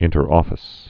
(ĭntər-ôfĭs, -ŏfĭs)